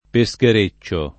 vai all'elenco alfabetico delle voci ingrandisci il carattere 100% rimpicciolisci il carattere stampa invia tramite posta elettronica codividi su Facebook peschereccio [ pe S ker %©© o ] agg. e s. m.; pl. m. -ci , pl. f. ‑ce